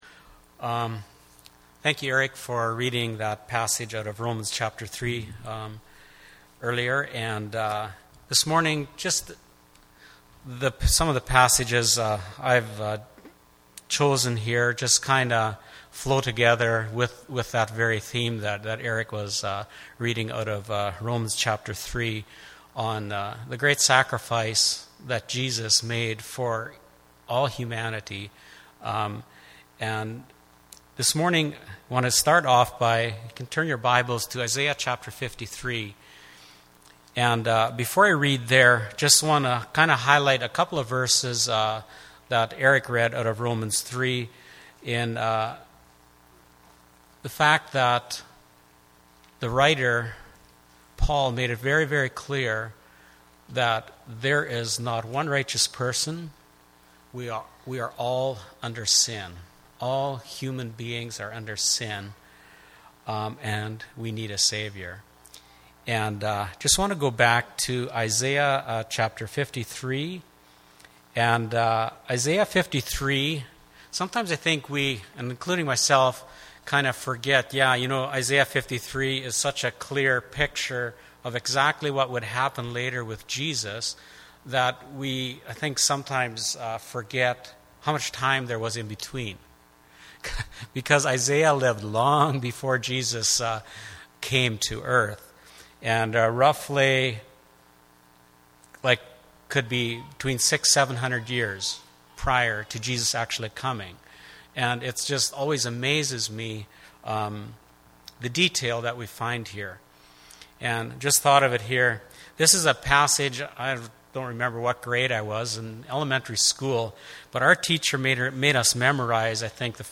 Communion Service